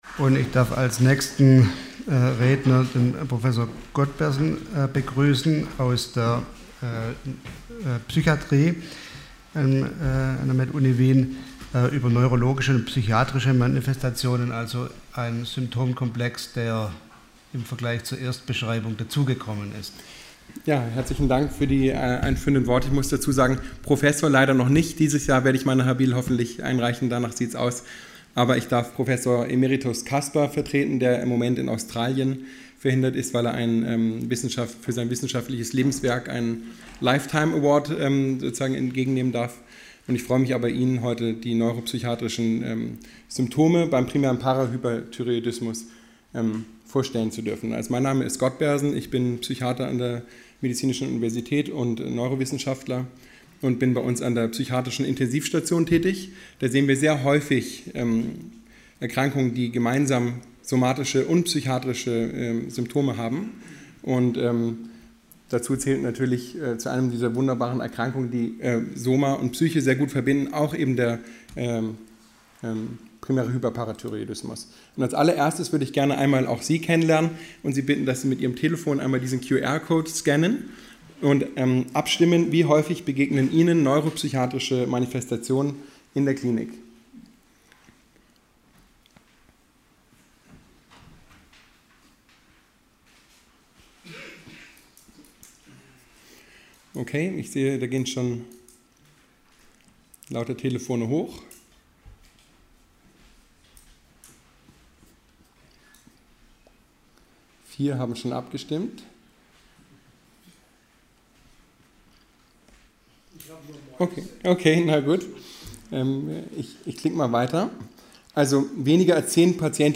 Sie haben den Vortrag noch nicht angesehen oder den Test negativ beendet.
Hybridveranstaltung